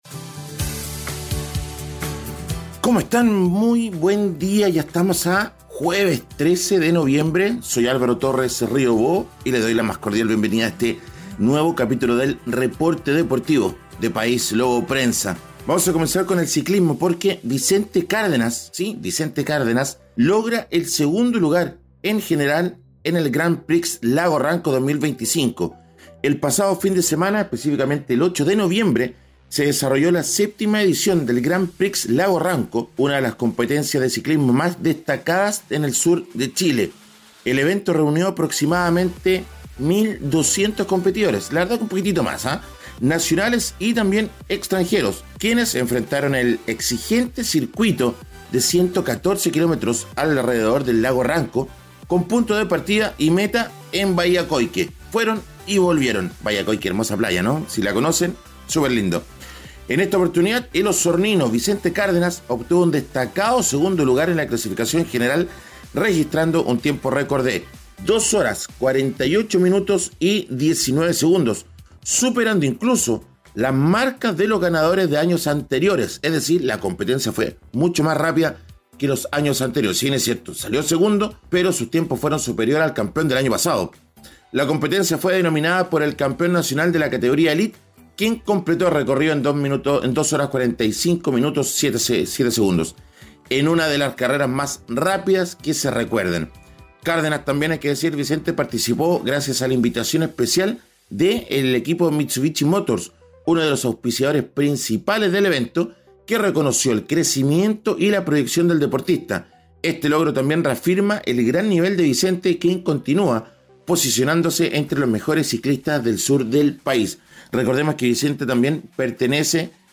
Reporte Deportivo